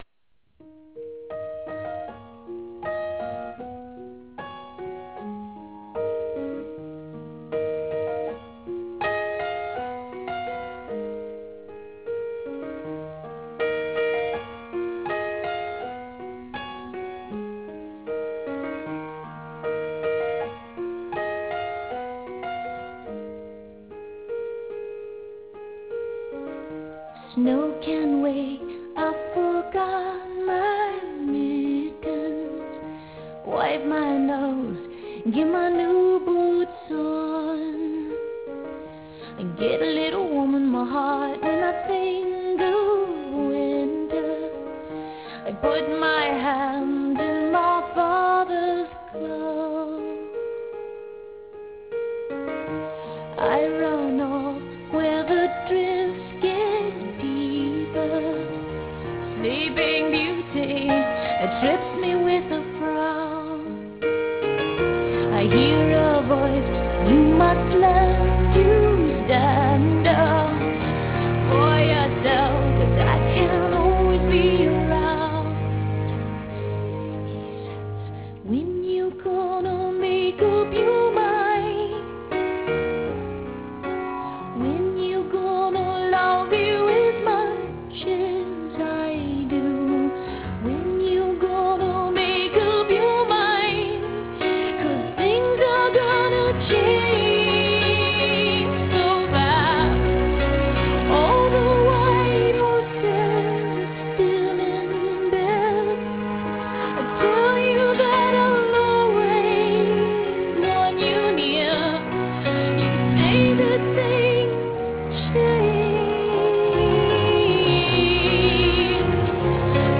compelling ballads